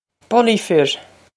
Bully fir!
This is an approximate phonetic pronunciation of the phrase.